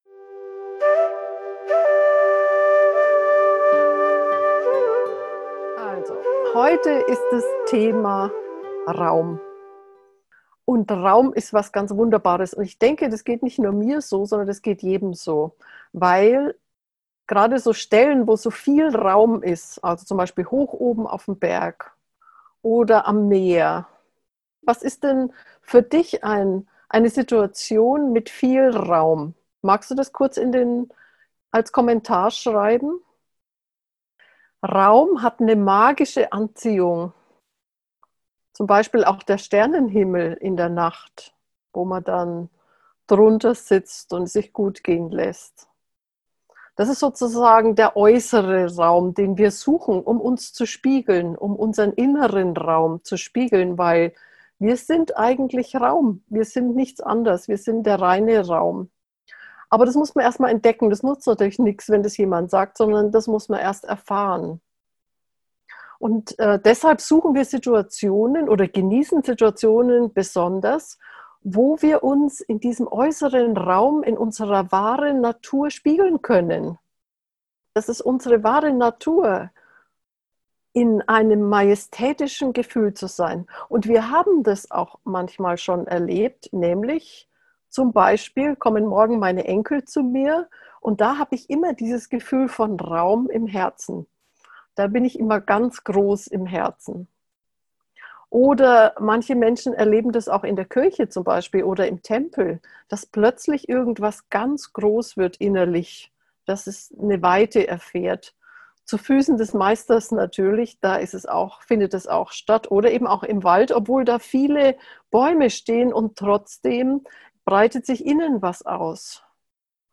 Mit dieser geführten Atemmeditation erforschst du die Zwischenräume zwischen dem Ein- und Ausatmen und entdeckst so den stillen, unendlichen Raum in dir.